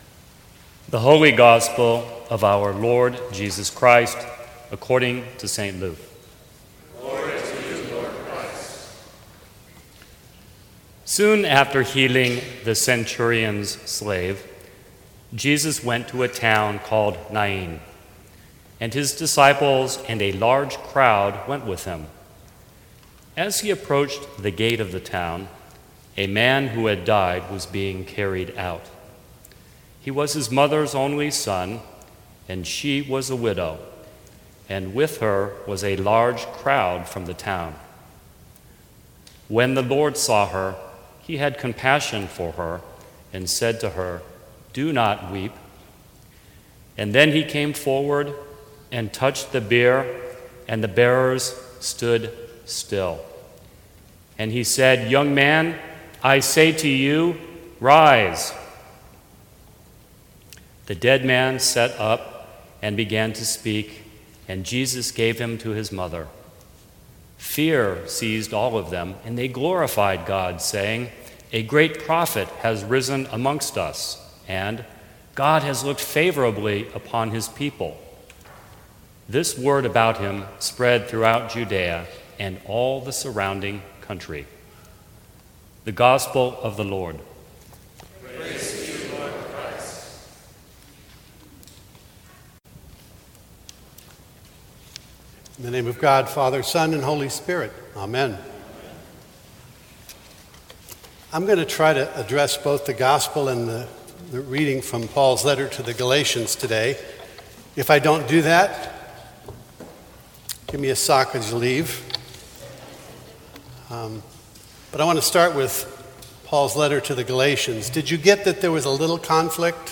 Sermons from St. Cross Episcopal Church Recognizing our RTD Moments.